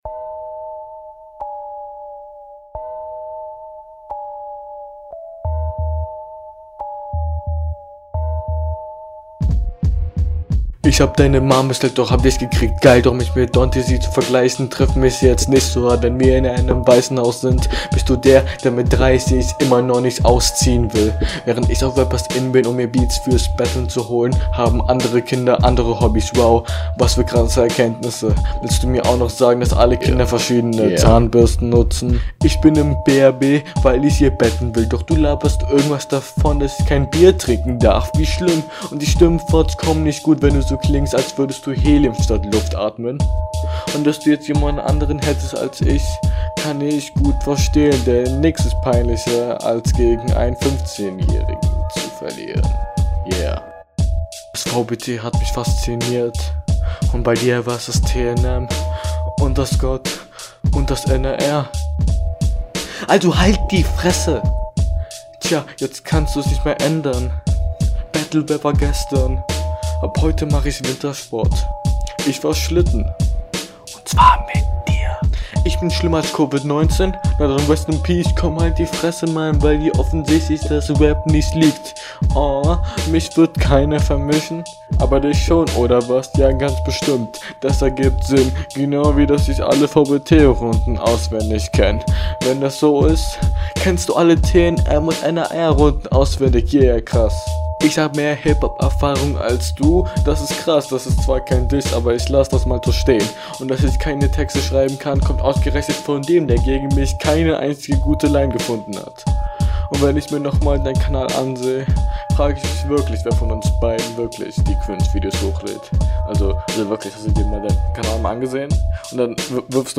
Flow: mehr vorgelesen als geflowt, versuch den Text dir einigermaßen einzuprägen bevor du aufnimmt Text: …
Flow: kein Flow, keine Zeilen Text: Nichts wirklich dabei was Punkte bringt Soundqualität: Tut wirklich …